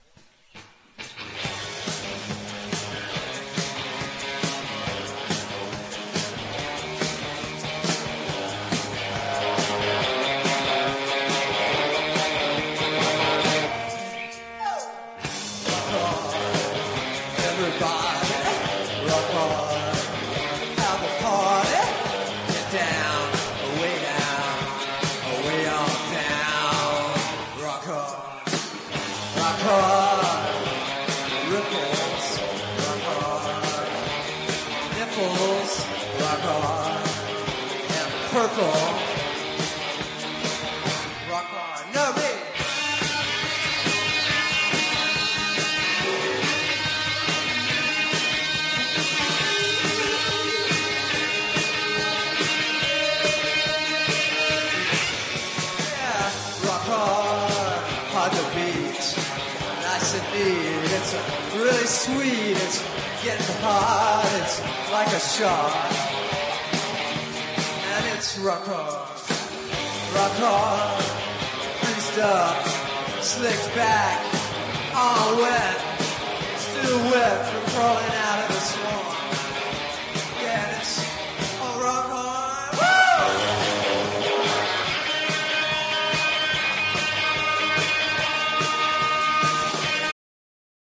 ����ɥ�ǤΥ饤�֤��Ͽ�����饤����!!��NEW WAVE